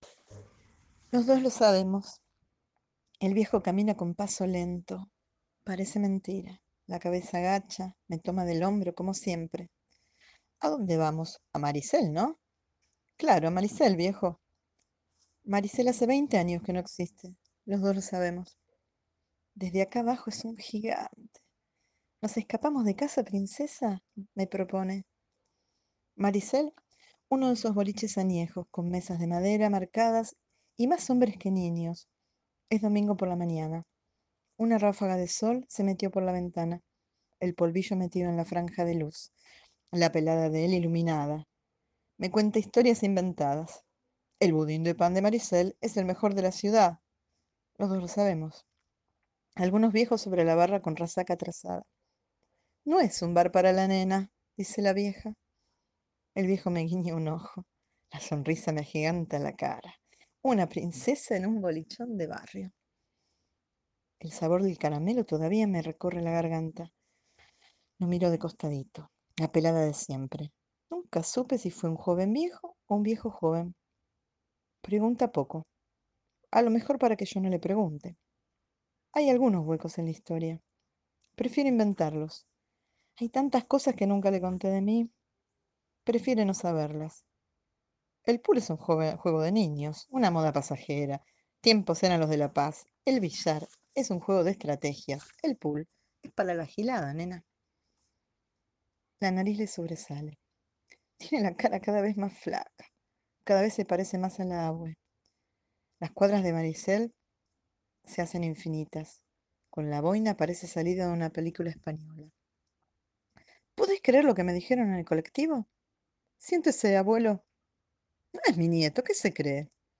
nos lee hoy un cuento